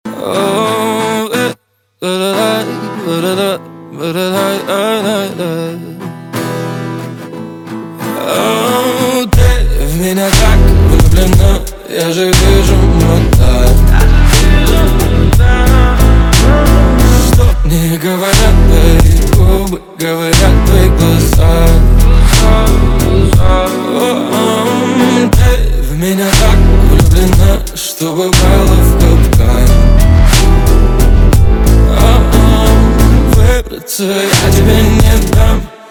Russian-language song